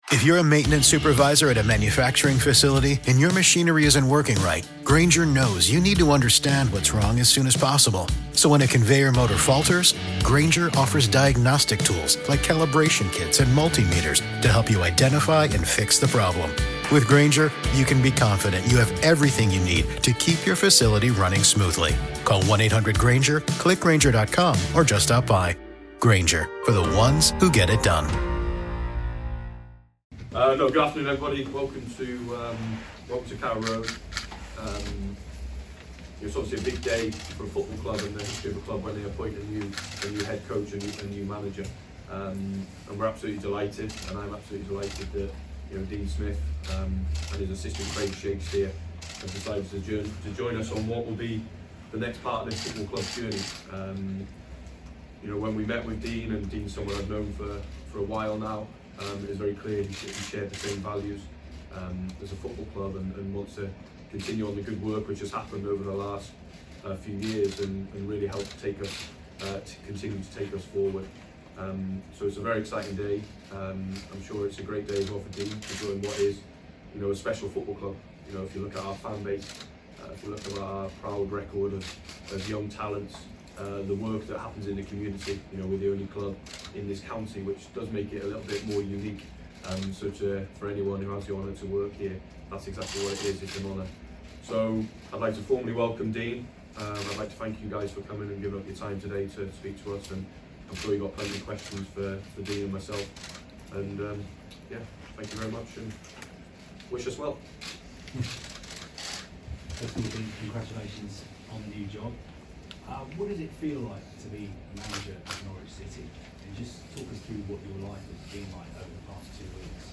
Dean Smith spoke to the media at Carrow Road on Wednesday afternoon as he was officially unveiled as Norwich City's new head coach.